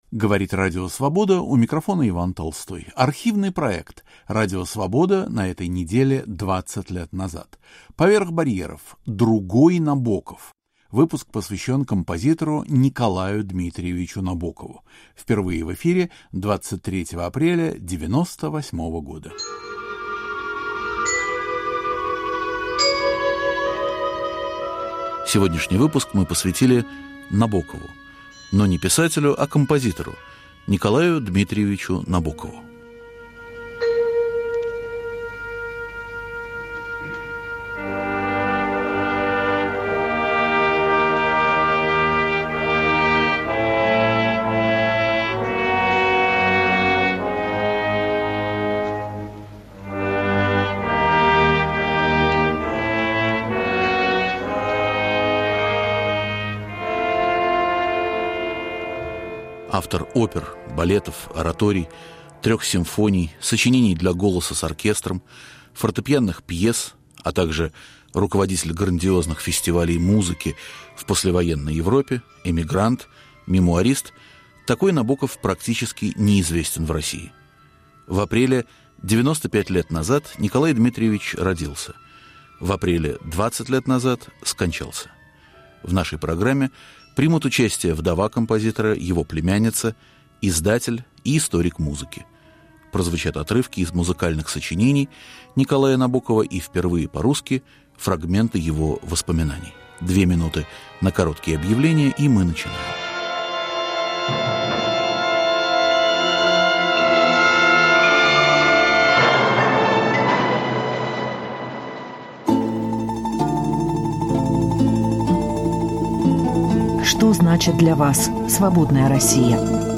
Впервые по-русски звучит отрывок из его воспоминаний.